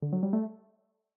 ringtone-B-nJXbbw.ogg